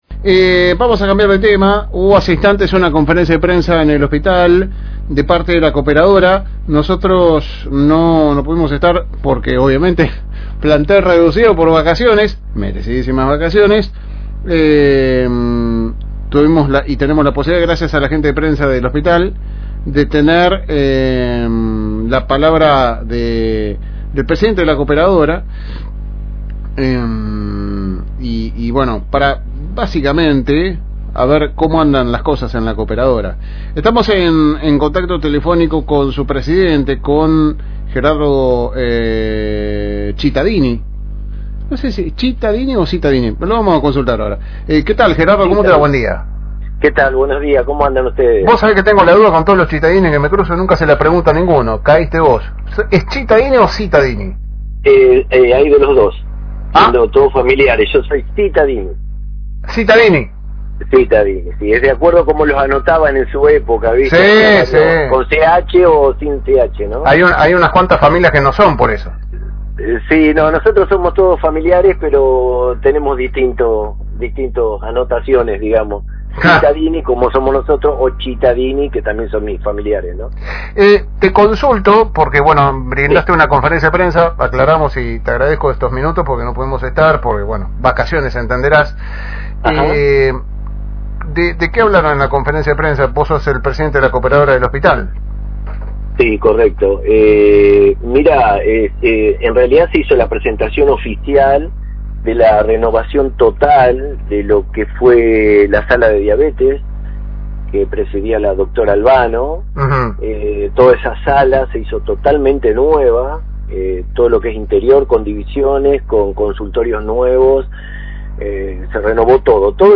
En una entrevista realizada durante la «Segunda Mañana»